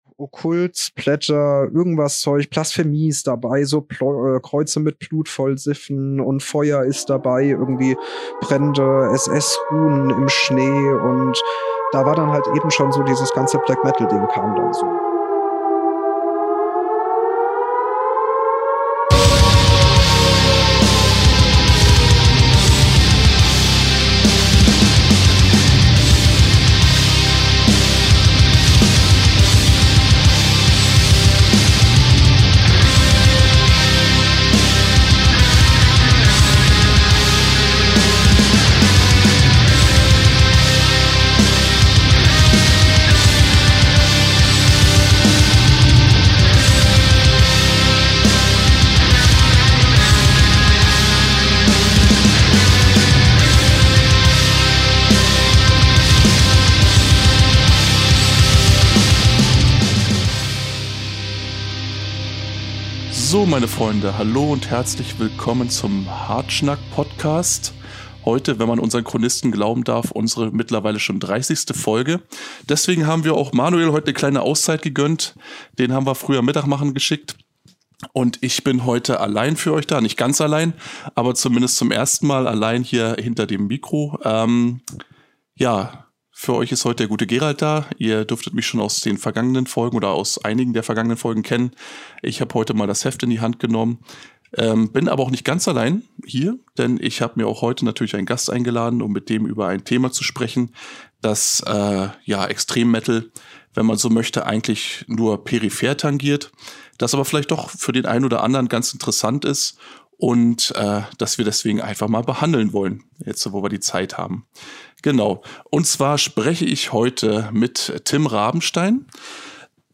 Hartschnack - der Extremmetal Podcast - jeden 2. Sonntag.